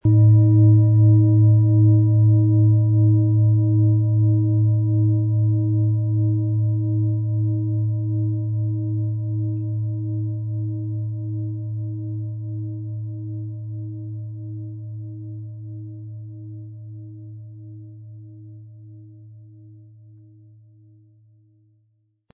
Es ist eine von Hand getriebene Klangschale, aus einer traditionellen Manufaktur.
• Mittlerer Ton: Eros
Im Audio-Player - Jetzt reinhören hören Sie genau den Original-Ton der angebotenen Schale.
Durch die traditionsreiche Fertigung hat die Schale vielmehr diesen kraftvollen Ton und das tiefe, innere Berühren der traditionellen Handarbeit
MaterialBronze